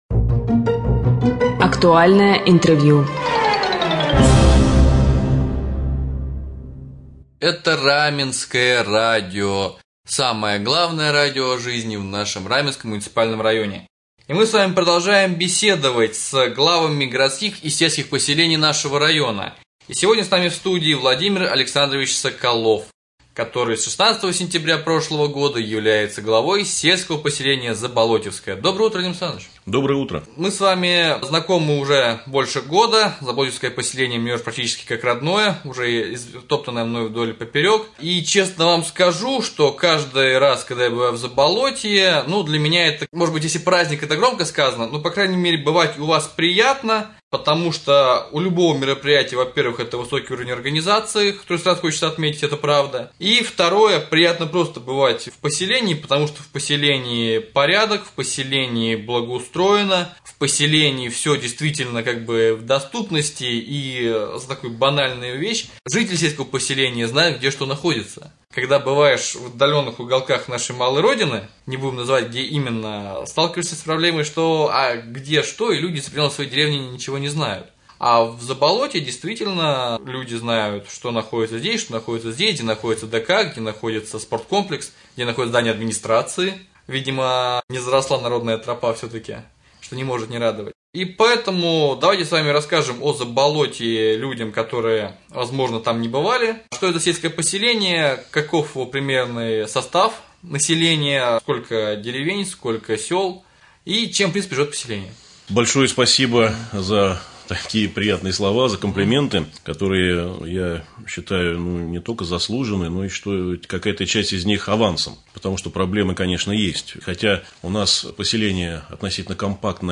Гость студии глава с/п Заболотьевское Соколов Владимир Александрович.
Интервью с главой с.п.Заболотьевское